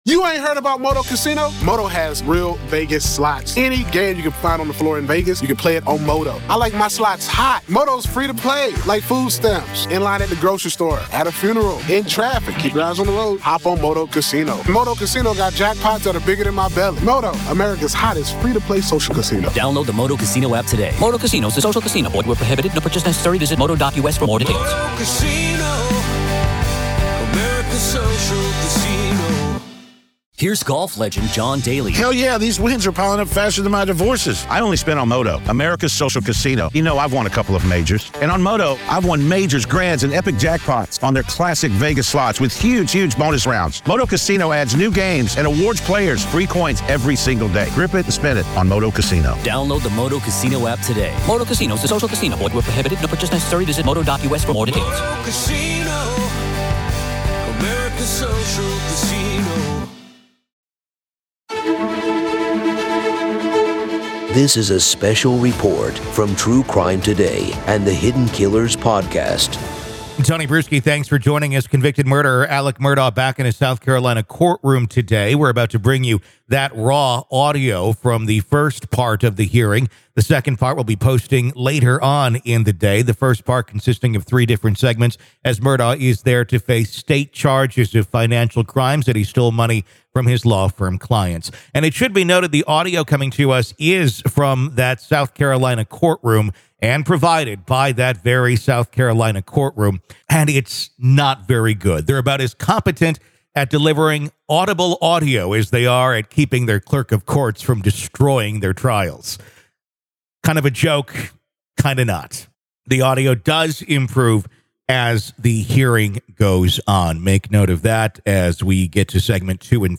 Join us as we delve into raw audio excerpts from the September 14th hearing of Alex Murdaugh.
This episode takes you straight into the heart of the courtroom, offering an unfiltered and immediate perspective of the proceedings. Hear firsthand the prosecution’s arguments, Murdaugh's defense, and the reactions of those present.